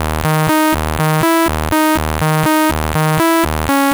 Transistor Out Eb 122.wav